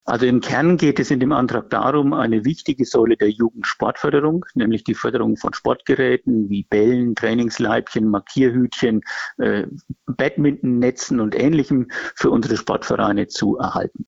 Eilantrag der Grünen: Jugendkulisse neu fördern -Interview mit Holger Laschka - PRIMATON
Ich spreche jetzt mit Holger Laschka, Stadtrat der Grünen, darüber, worum es bei dem Antrag geht: